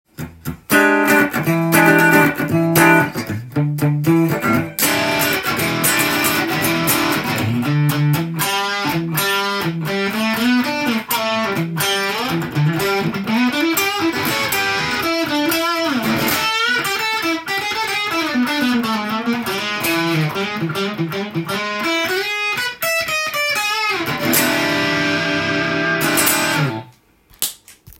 KORG　が出しているオーバードライブ　Nu:tekt　です。
このエフェクターの真空管の温かみのある音がします。
当教室では、トランジスタアンプを使用しているのですが
このエフェクターを押すと真空管のような音がしました。